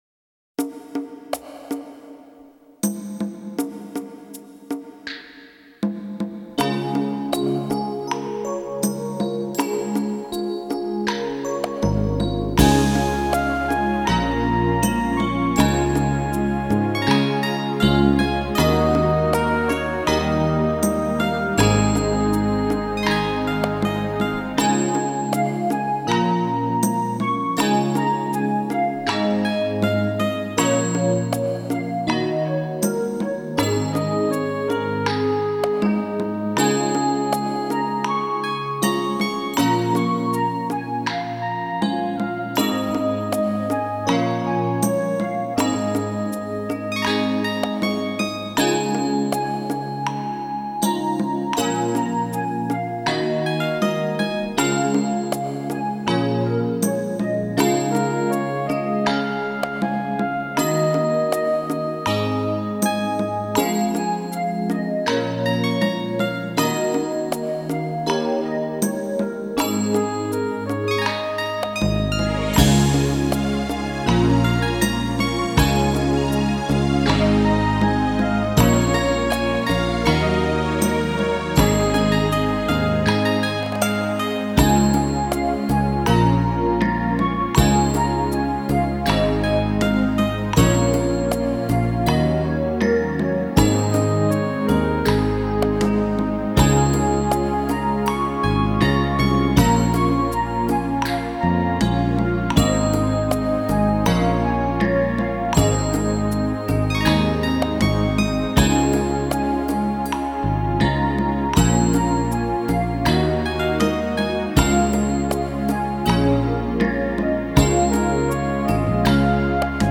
Db调 2/4
吉他
二胡
笛子
扬琴